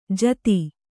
♪ jati